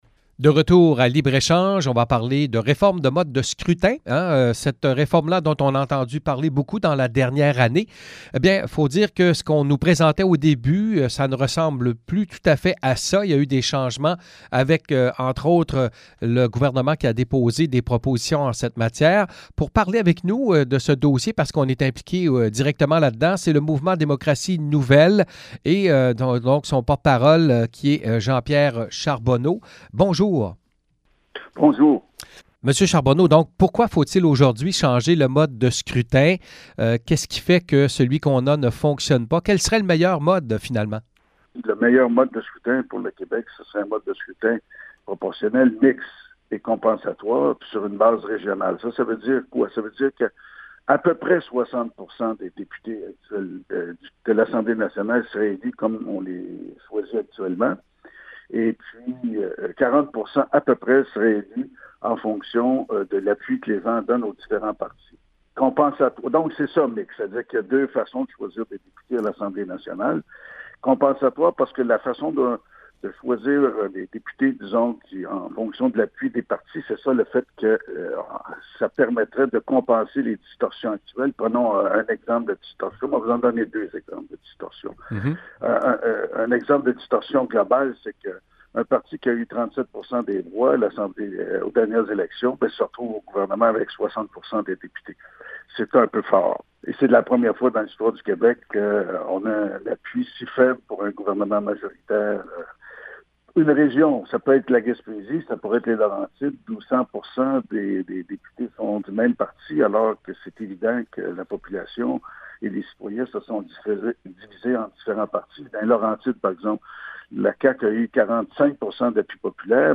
Écoutez nos entrevues sur le sujet.